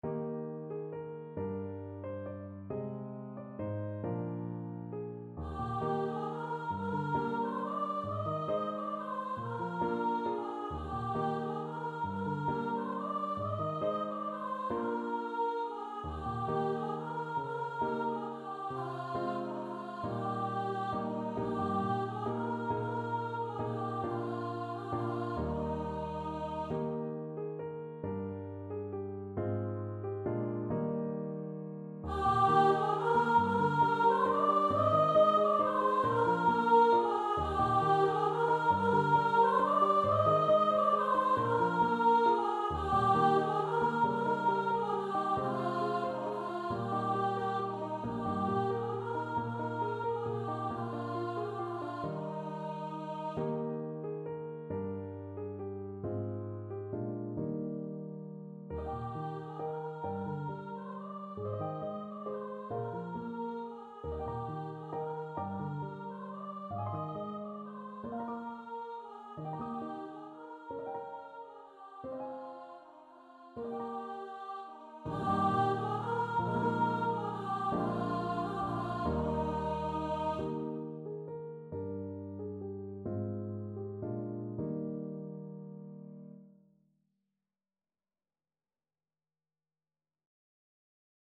Free Sheet music for Choir (SATB)
.=45 Gently Lilting .=c.45
6/8 (View more 6/8 Music)